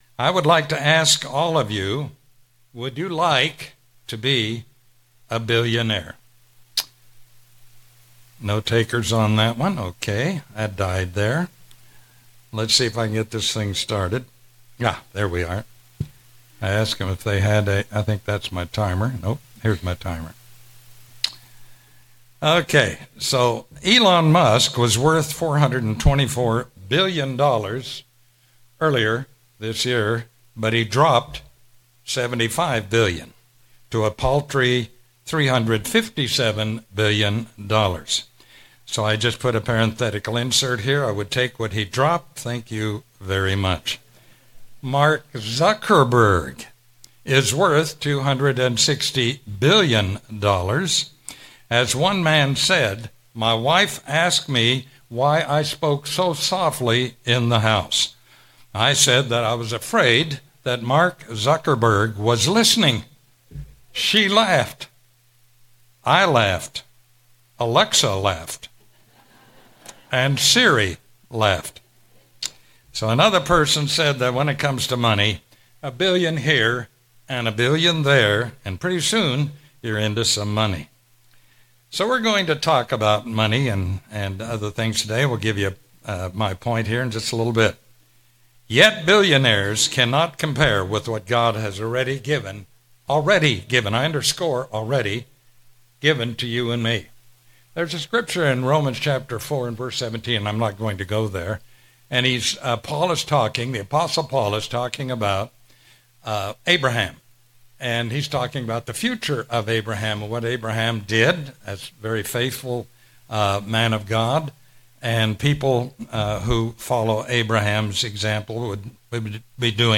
Sermons
Given in Daytona Beach, Florida